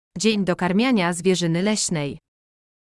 Wenn du so neugierig bist wie wir Wichtel, willst du natürlich wissen, wie man den polnischen Namen des Tages – Dzień Dokarmiania Zwierzyny Leśnej – ausspricht, stimmt’s? Bitteschön, so hört er sich in Polnisch an: